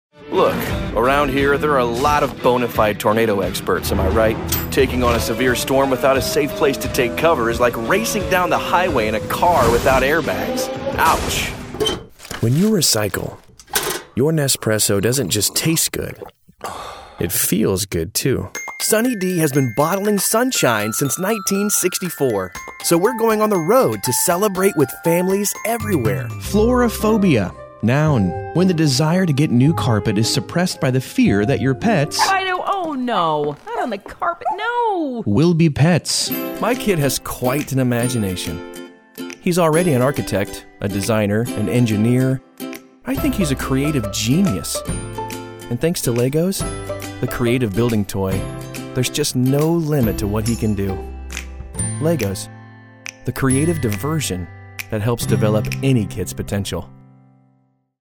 Male
I have a conversational, natural and Everyman type of voice.
Corporate/Explainer Type Vo
Television Spots
Commercial Showreel 2
Words that describe my voice are Conversational, Natural, Everyman.